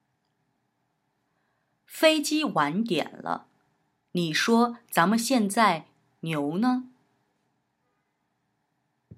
Sprich: Die jeweils neue Vokabel wurde durch ein Platzhalterwort ersetzt.